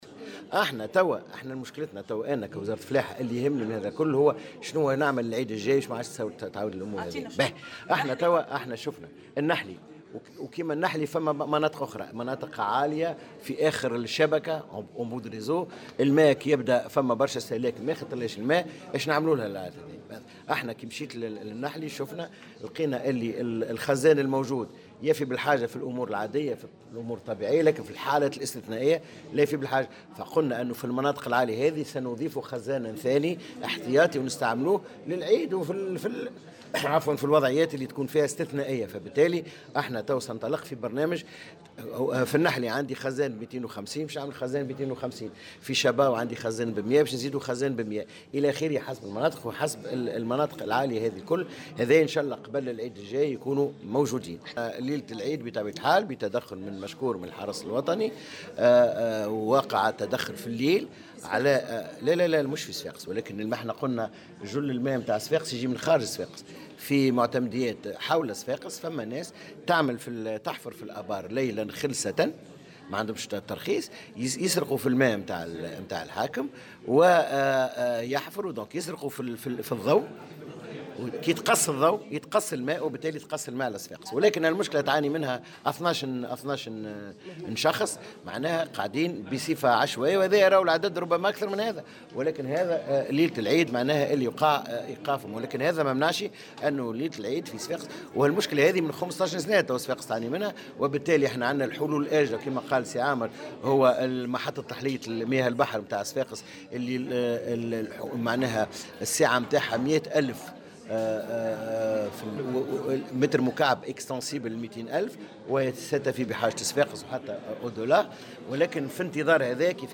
وأضاف في تصريح اليوم لمراسل "الجوهرة أف أم" على هامش ندوة صحفية للجنة تقييم التصرف في إدارة مياه الشرب أيام العيد، أن الوزارة ستعمل على إحداث خزانات ماء إضافية لتلافي مشاكل التزويد خلال السنة المقبلة.